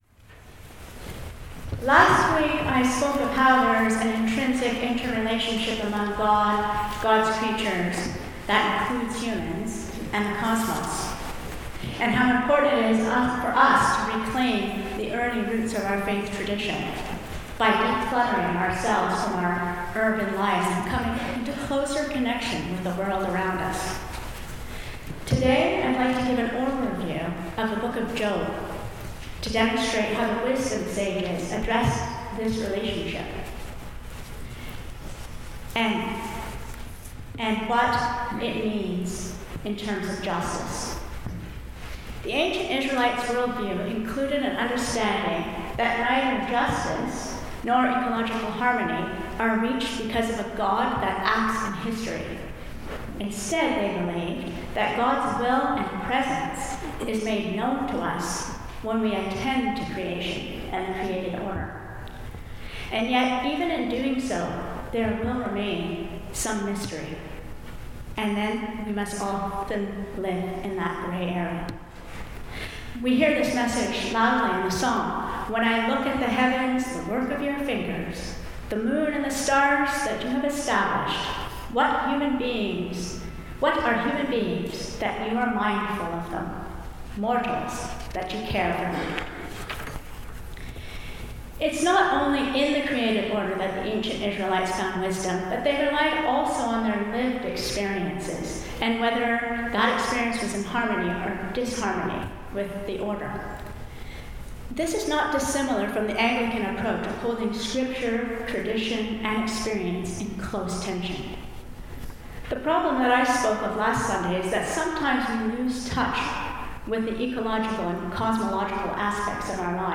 Sermons | St John the Evangelist